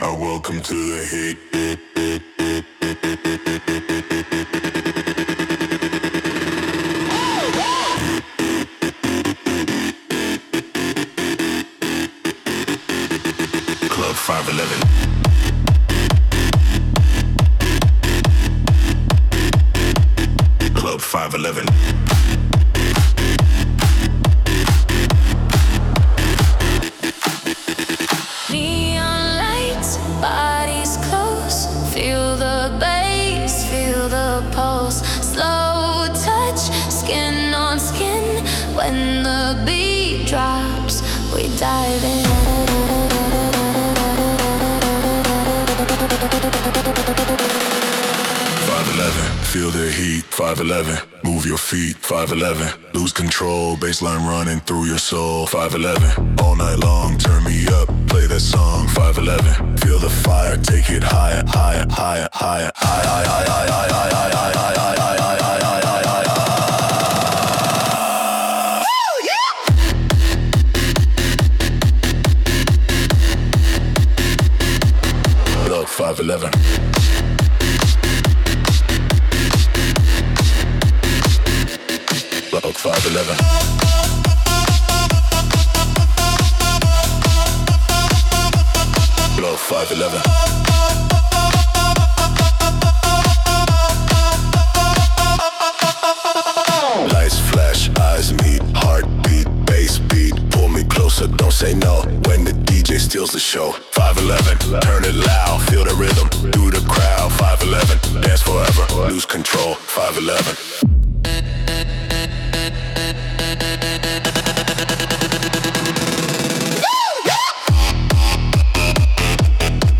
Was working on another song, but had some issues getting the EDM beat I wanted, so decided to switch gears and write a simpler version but a different theme.